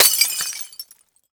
glass_fall01hl.ogg